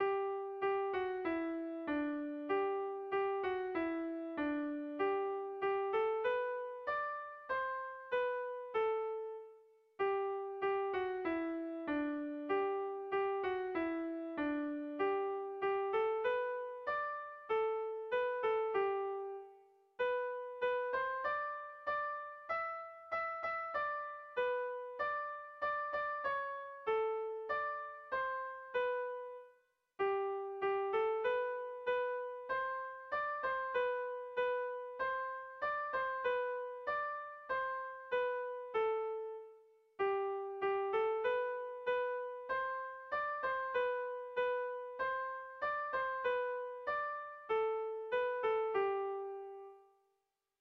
Bertso melodies - View details   To know more about this section
Irrizkoa
AABDE